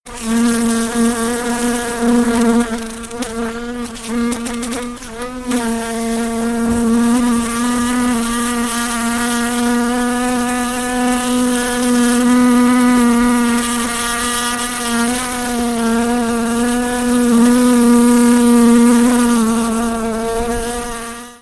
دانلود صدای مگس 1 از ساعد نیوز با لینک مستقیم و کیفیت بالا
جلوه های صوتی